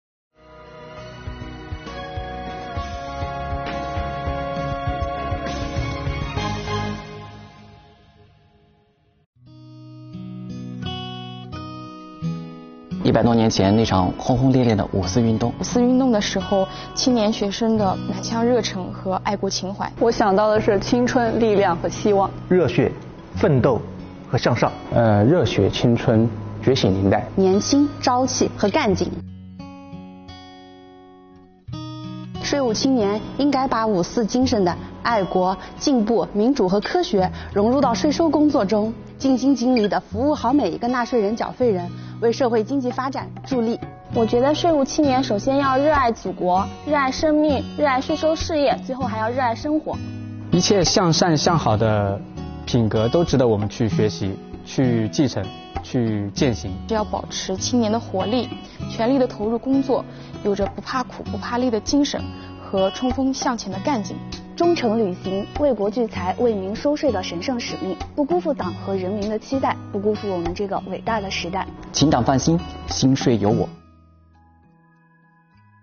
“提到五四，你首先想到的是什么？”“税务青年应该如何传承五四精神？”五四青年节前夕，安徽税务青年们面对镜头，说出了自己理解的五四精神，他们表示，税务青年应该把五四精神融入到税收工作中，尽心尽力服务好每一位纳税人和缴费人，为社会经济发展助力。